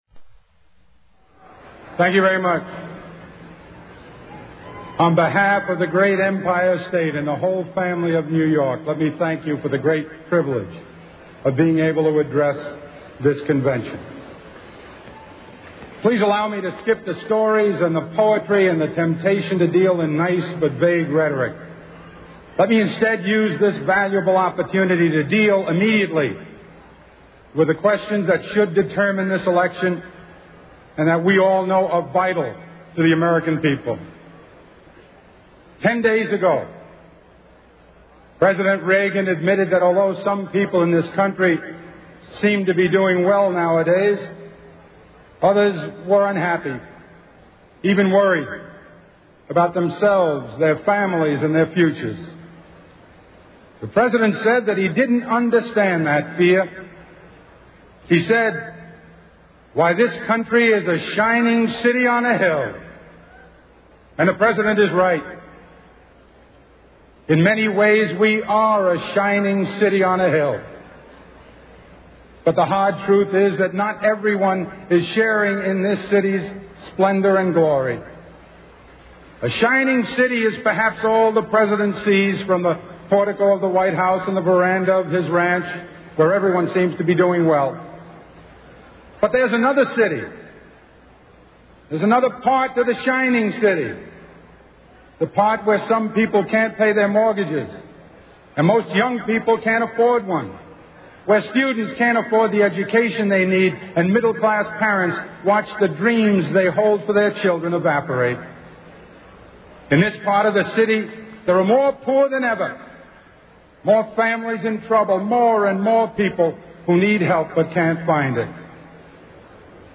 Mario Cuomo: 1984 Democratic National Convention Keynote Address